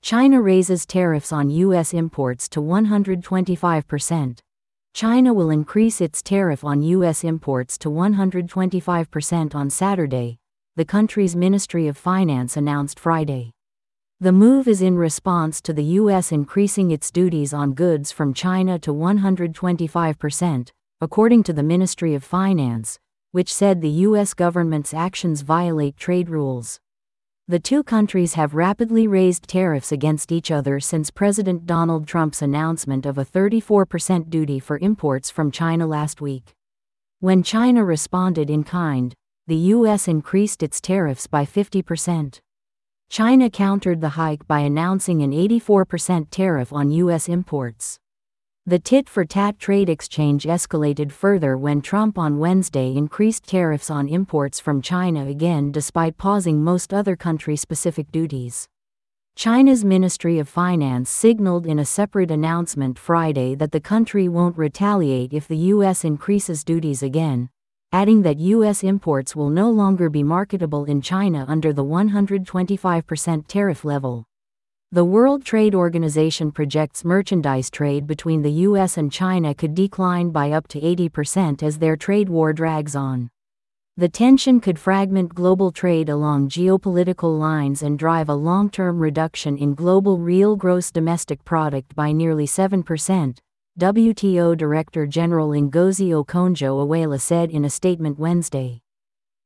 This audio is auto-generated. Please let us know if you have feedback.